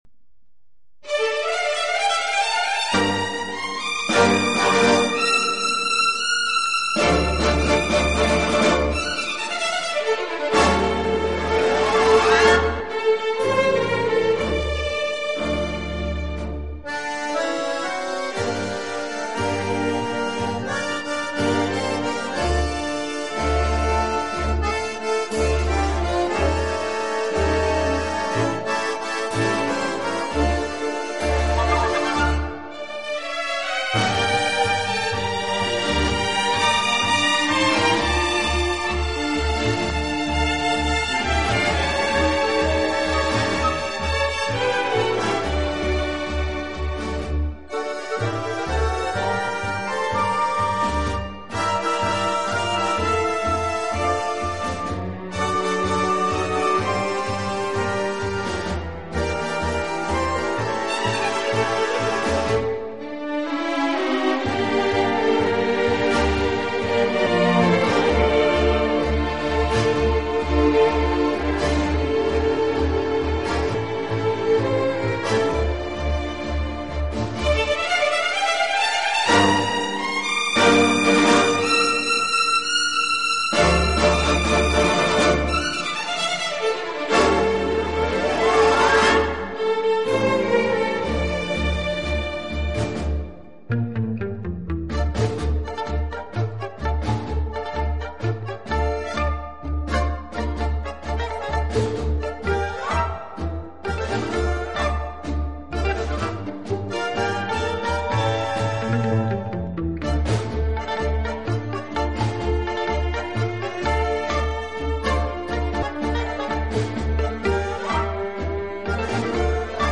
乐背景的不同，以各种乐器恰到好处的组合，达到既大气有力又尽显浪漫的效果。
乐队的弦乐柔和、优美，极有特色，打击乐则气度不凡，而手风琴、钢琴等乐器